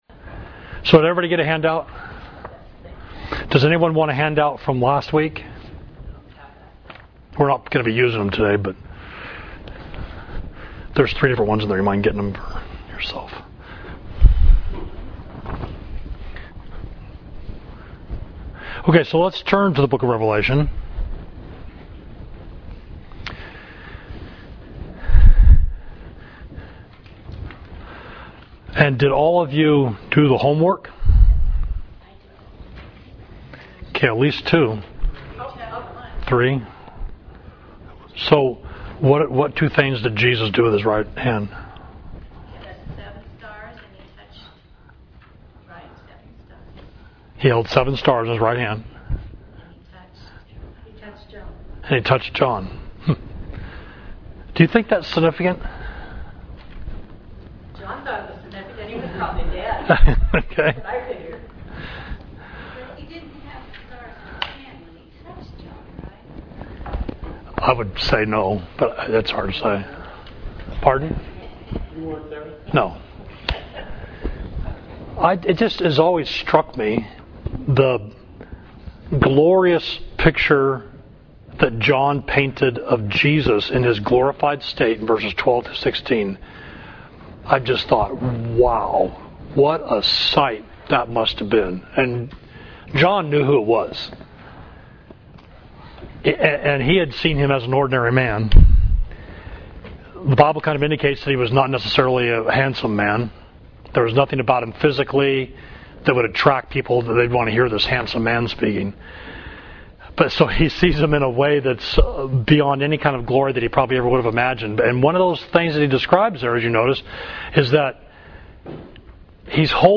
Class: Introducing the Book of Revelation; Revelation 1.1–3